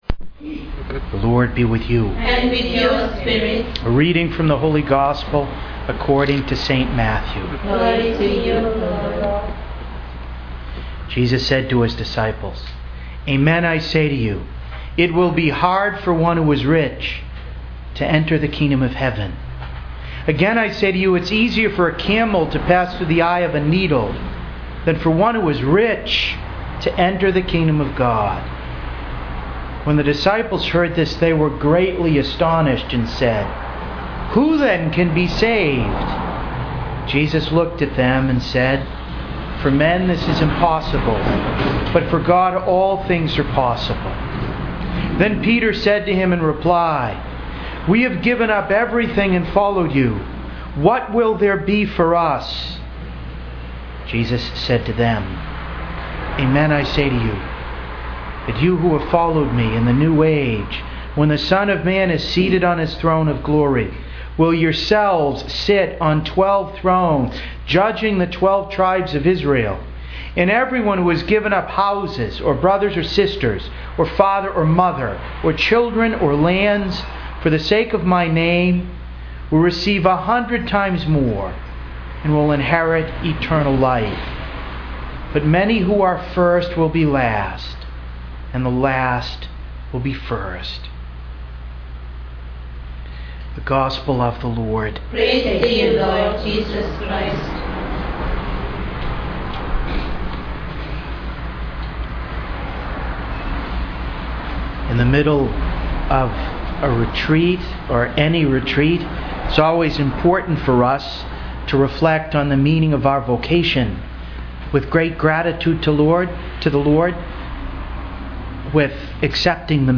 The following points were made for this homily for Tuesday of the 20th Week of Ordinary Time, Year I: